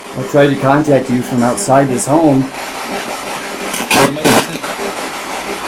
If you listen carefully, you may a young female speak right before the male asks his question... Man boldy asks me a question I can hear a faint female voice say maybe, "I remember!" Then a very loud and clear male voice asking, "To torment her?" show/hide spoiler Back to Villisca Axe Murder House Evidence Page